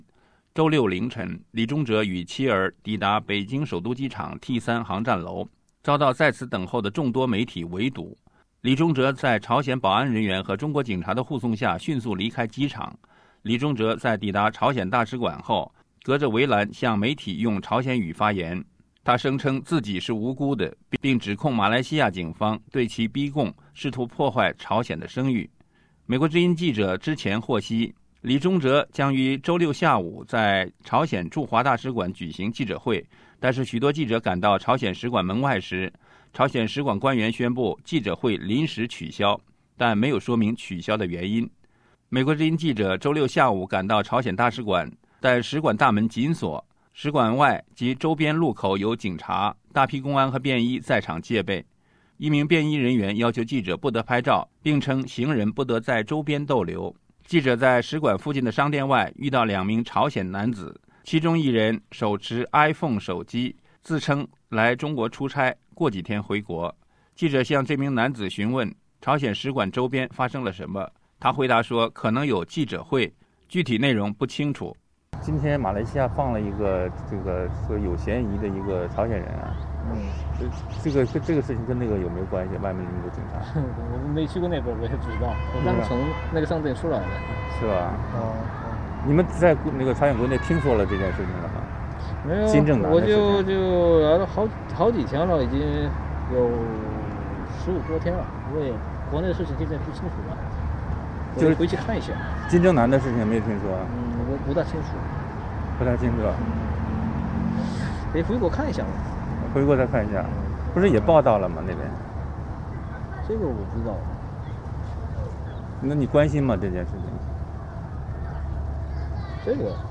记者在使馆附近的商店外遇到两名朝鲜男子，其中一人手持iPhone手机，自称来中国出差，过几天回国。记者向这名男子询问附近使馆周边发生了什么，他回答说可能有记者会，具体内容不清楚。